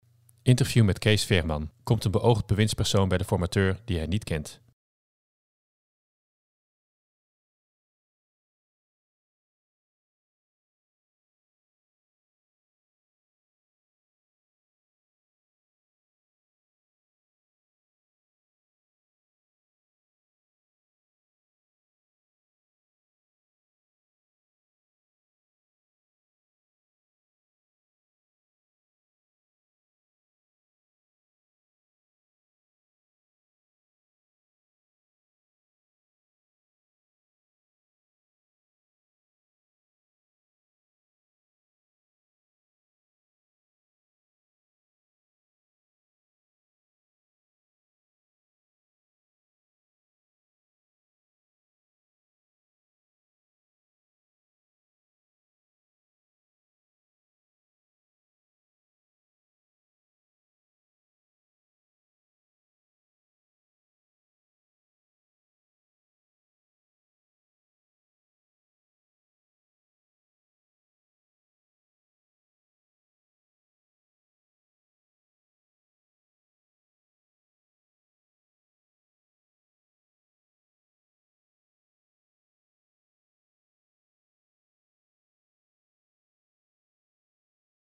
Interview met Cees Veerman